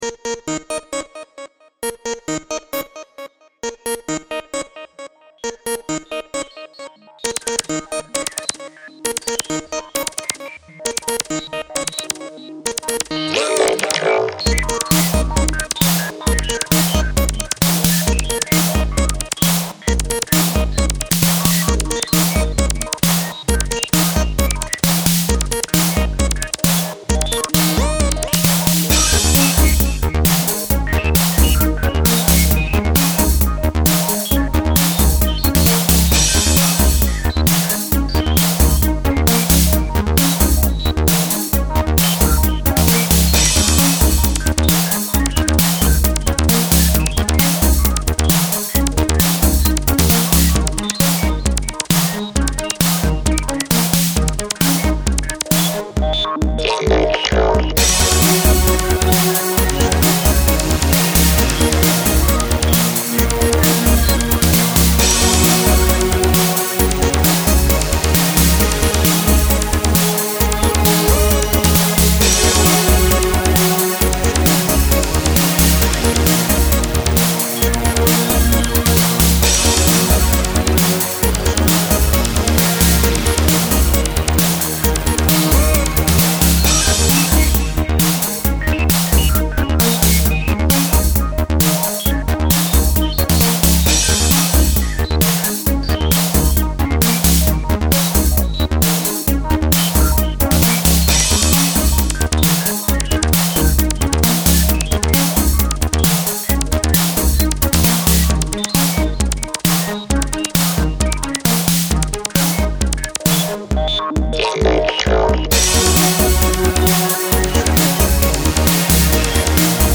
dance/electronic
Techno
Eighties/synthpop